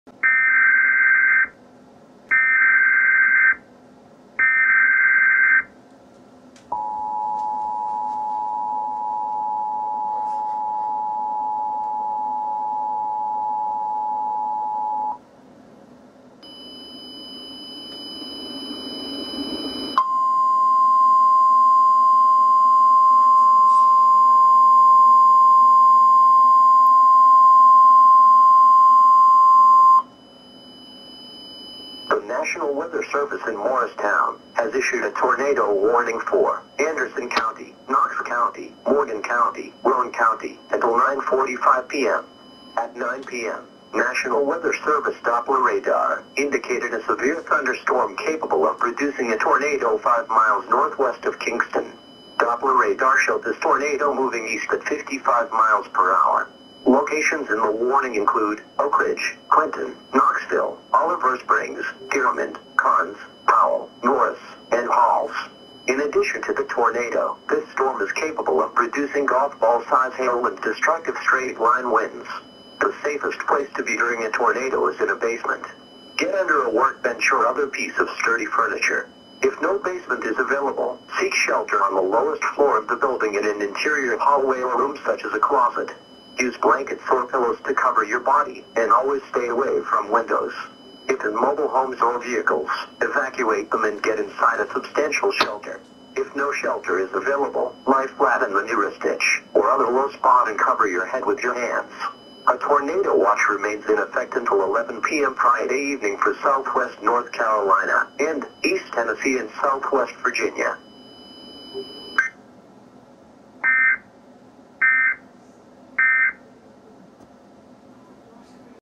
Emergency Alert System
ORIGINAL-Emergency-Alert-System-Tornado-Warning-for-Knoxville-TN-March-2-2012.mp3